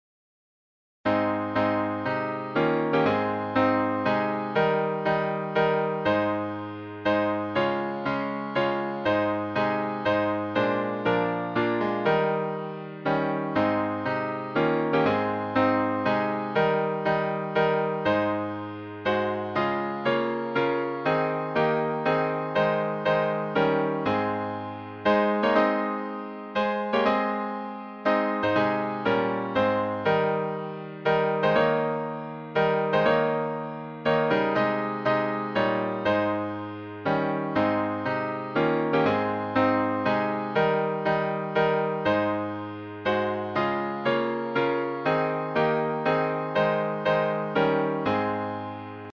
Hymn lyrics and .mp3 Download